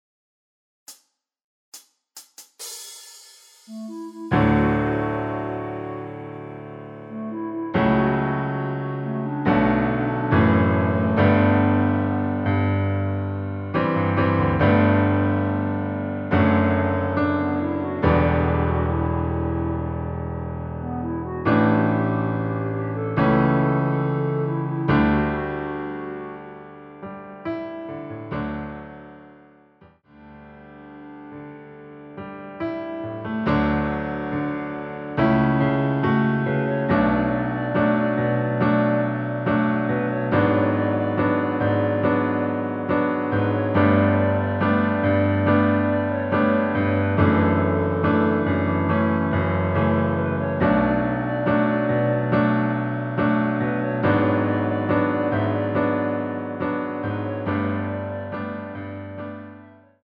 반주가 피아노 하나만으로 제작 되었습니다.(미리듣기 확인)
전주없이 노래가시작되는곡이라 카운트 만들어 놓았습니다.
원키에서(-2)내린 (Piano Ver.)멜로디 포함된 MR입니다.(미리듣기 확인)
앞부분30초, 뒷부분30초씩 편집해서 올려 드리고 있습니다.